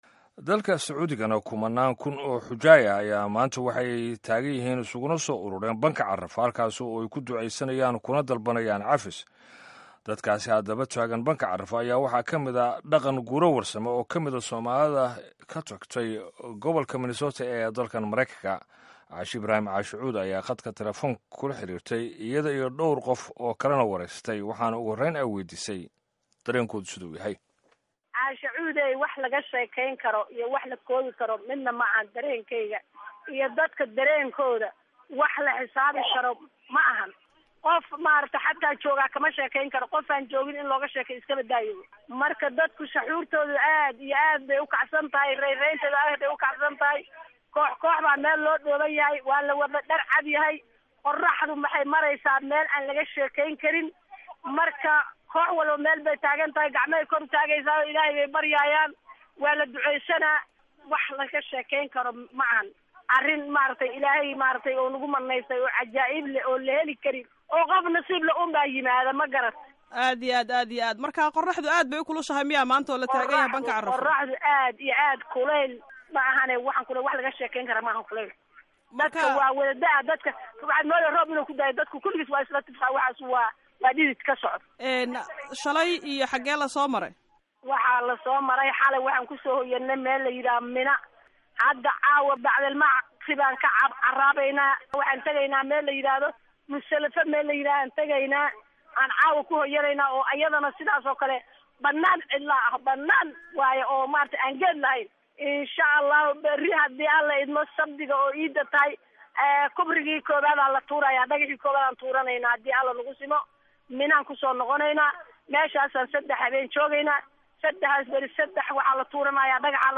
Dhageyso wareysiga Xujeyda taagnaa Buurta Carafo